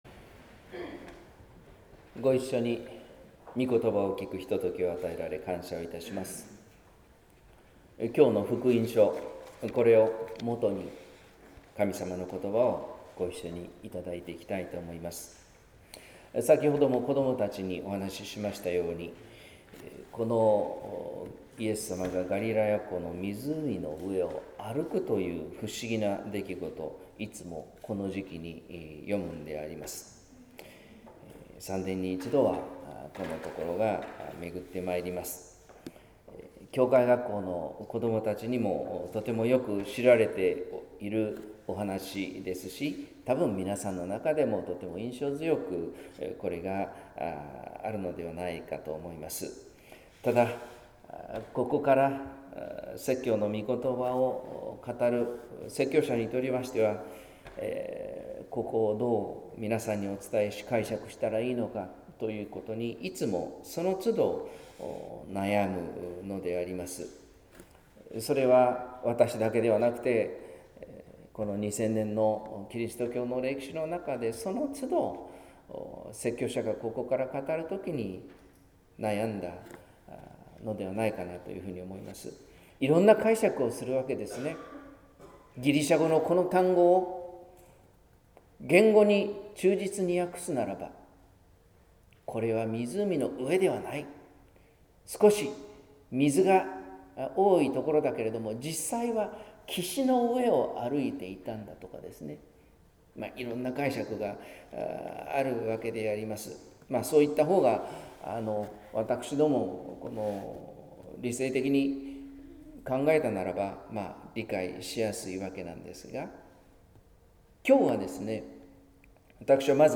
説教「通り過ぎるイエス」（音声版）